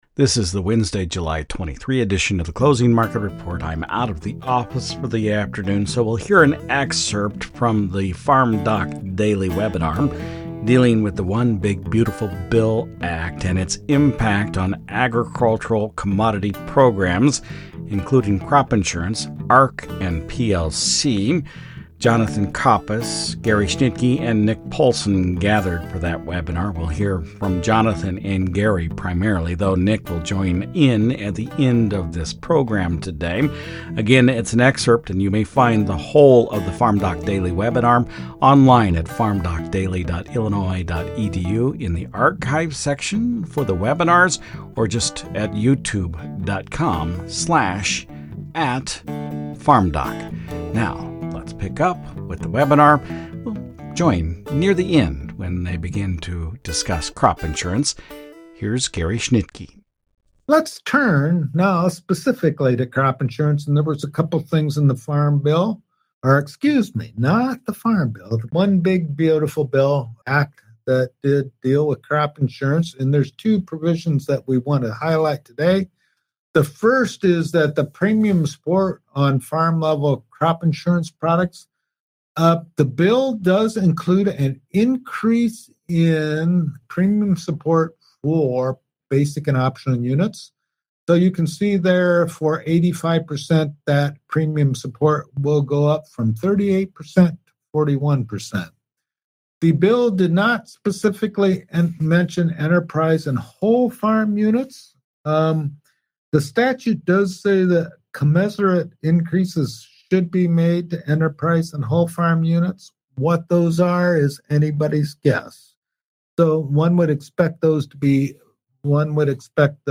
Today's episode is an excerpt from the farmdoc webinar detailing how the One Big Beautiful Bill Act has changed crop insurance and farm programs.